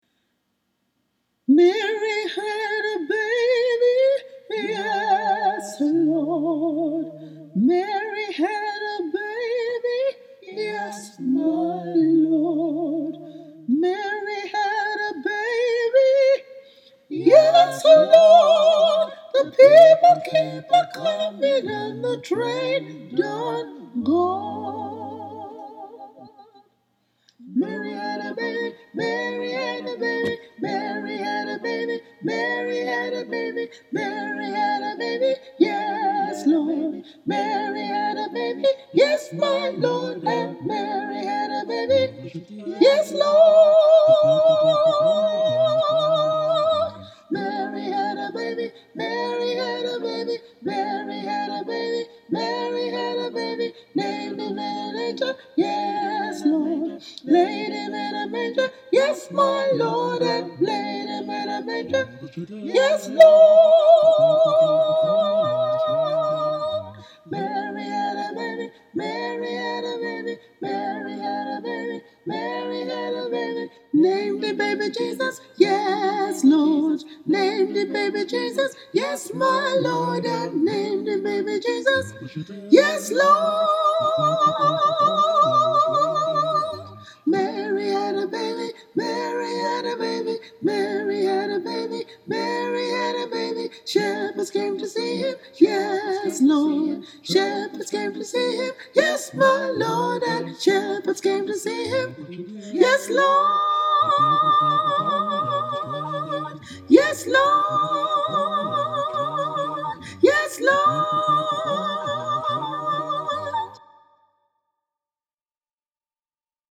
mary-had-a-baby-soprano.mp3